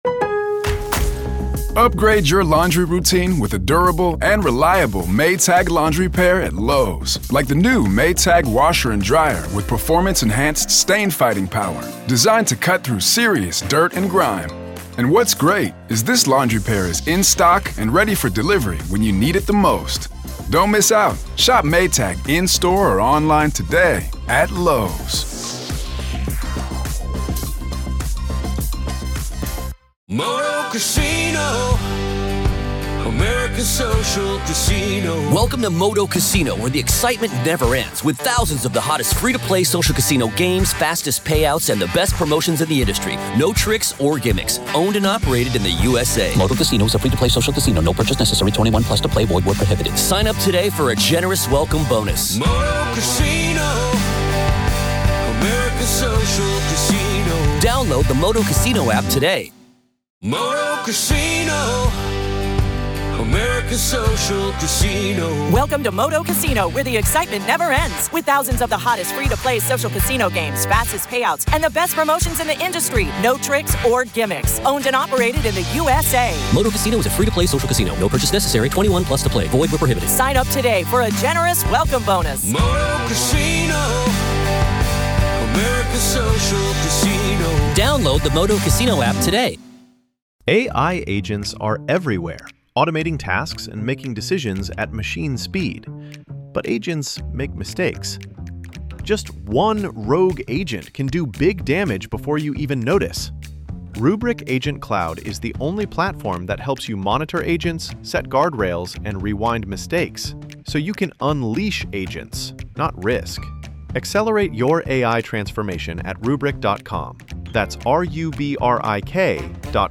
conversation unpacks the truth with insight only a former federal agent can deliver